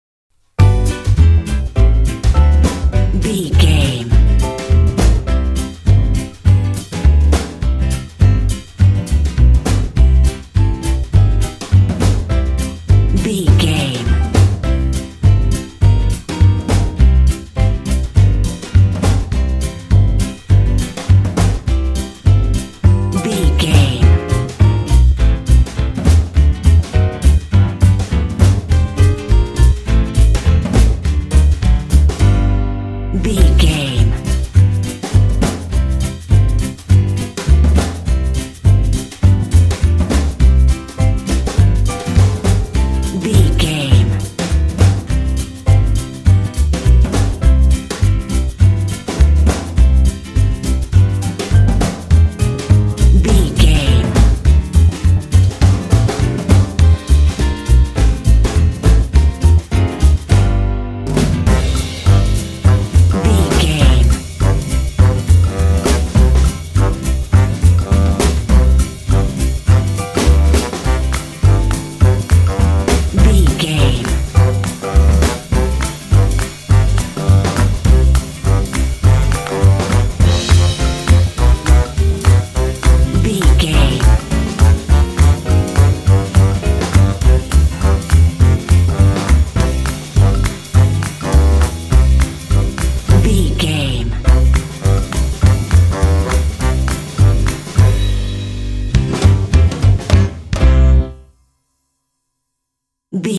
Ionian/Major
playful
uplifting
calm
cheerful/happy
drums
bass guitar
acoustic guitar
piano